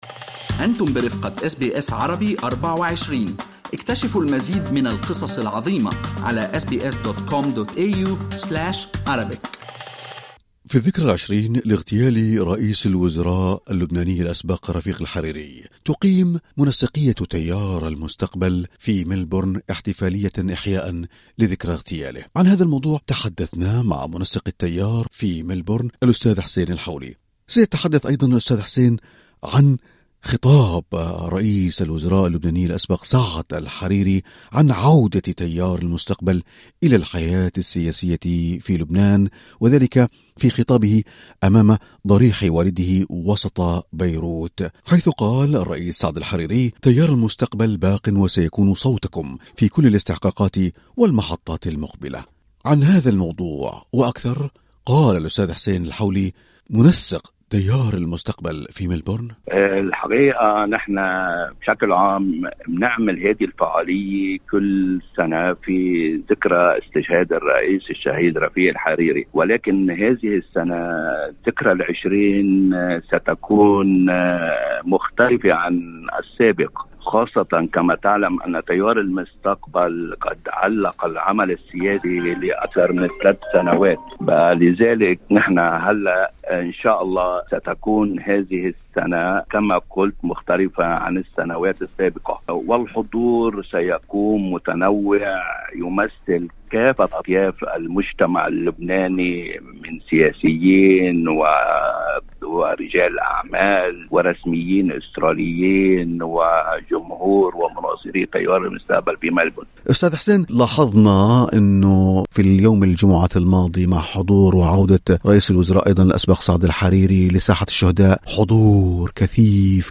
في حديثه مع إذاعة أس بي أس عربي